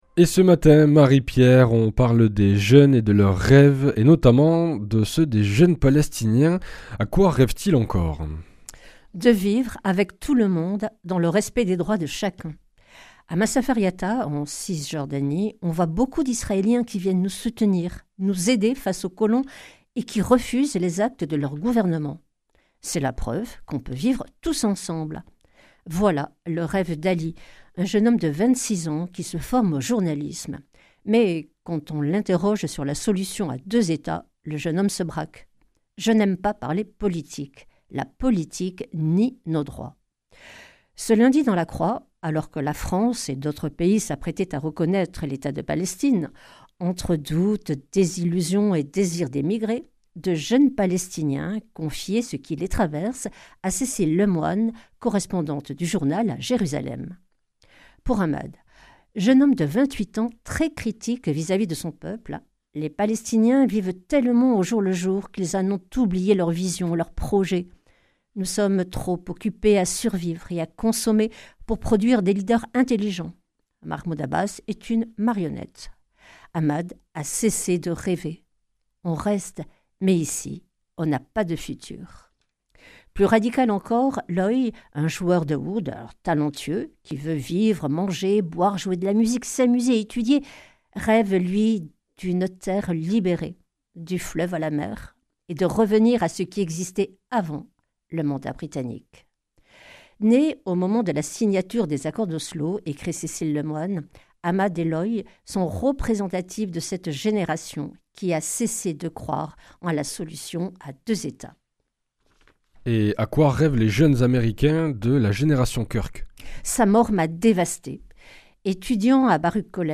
Revue de presse
Une émission présentée par
Journaliste